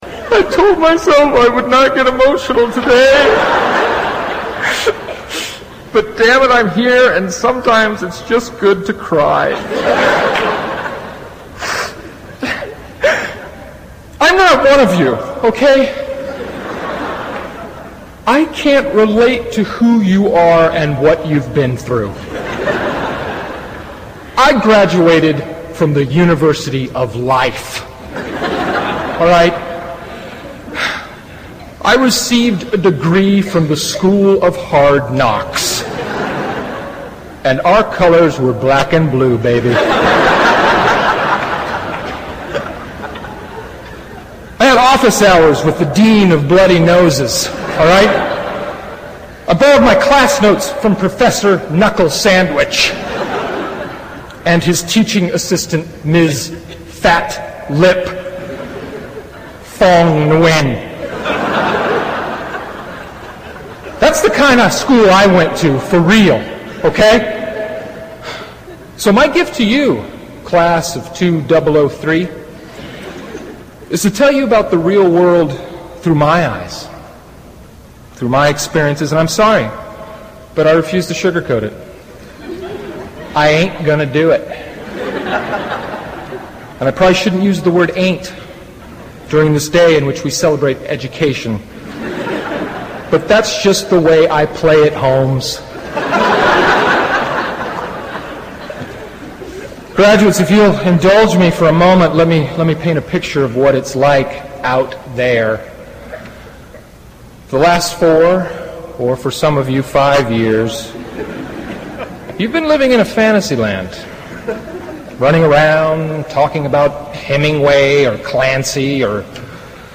Tags: Class of 2003 Will Ferrell Harvard commencement speech audio clip